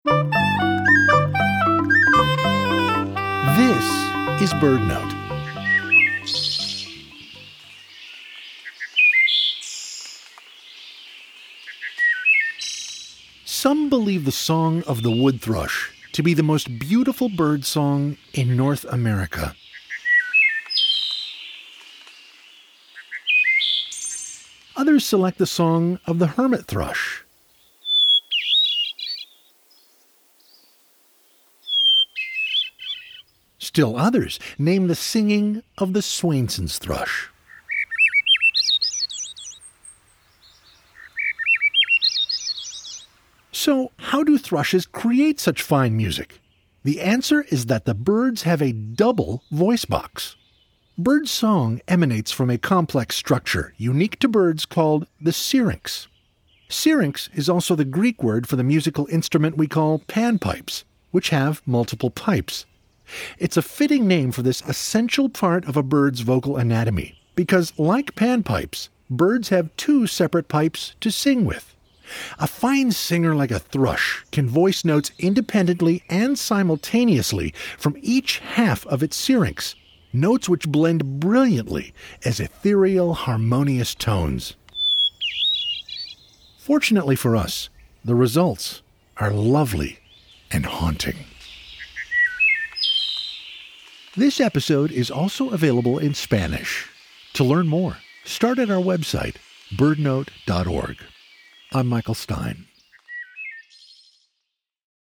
The answer is that the birds have a double voice box, unique to them, called the syrinx. A fine singer like a thrush can voice notes independently and simultaneously from each half of its syrinx, notes which blend brilliantly as ethereal, harmonious tones.